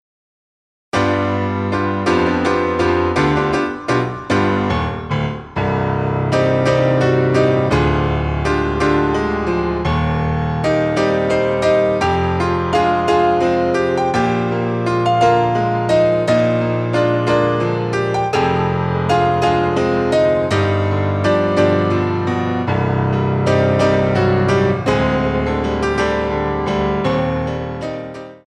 GRAND ALLEGRO II